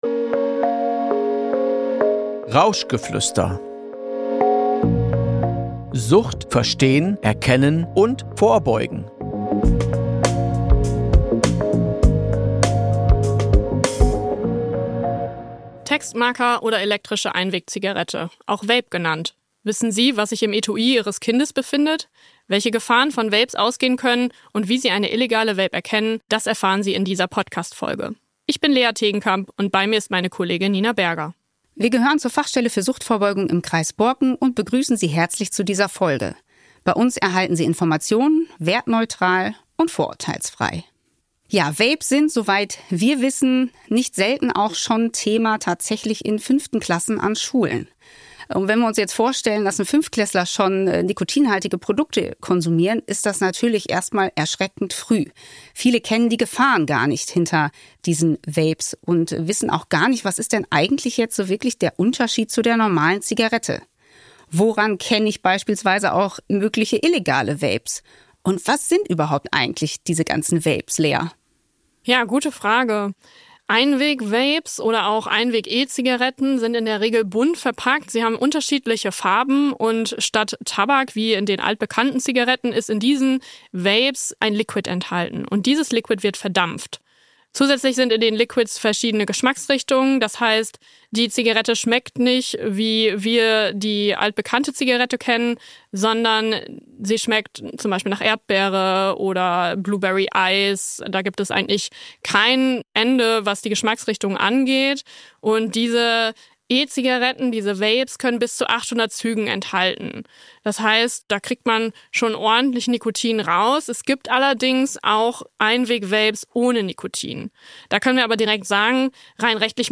Welche Gefahren verbergen sich hinter nicht zugelassenen Vapes und woran können Erziehende diese erkennen? Abschließend werden Handlungsempfehlungen geteilt, die für Erziehende im Umgang mit (konsumierenden) Kindern und Jugendlichen hilfreich sein können. Ein Gespräch, das informiert, einordnet und stärkt – für alle, die mit jungen Menschen im Austausch bleiben wollen.